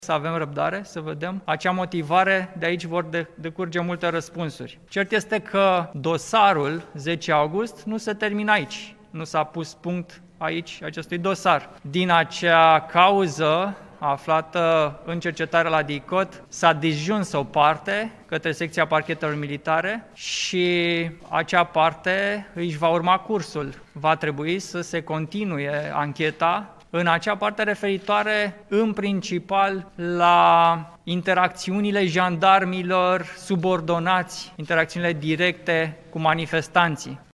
Într-o conferință de presă, Stelian Ion face apel pentru așteptarea motivării decizie Tribunalului București prin care a fost respinsă cererea de redeschidere a dosarului  care îi viza pe foștii șefi ai Jandarmerie Române: